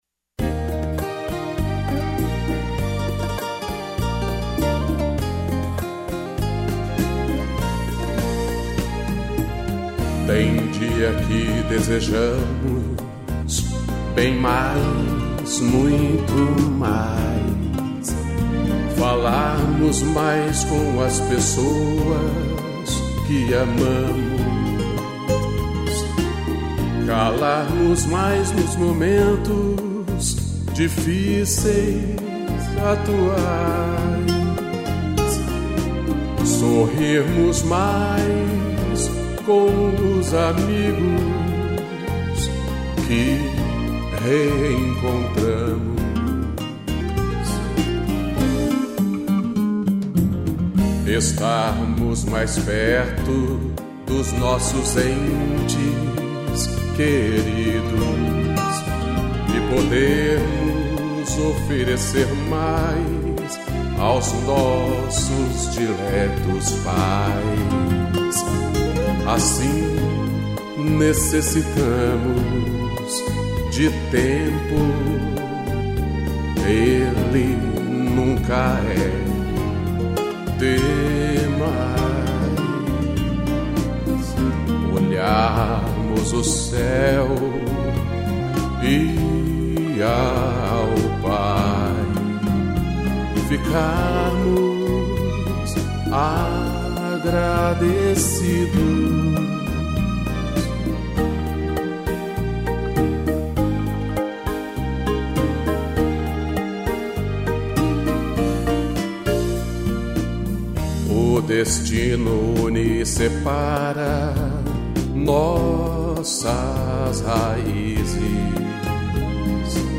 voz e violão
strigs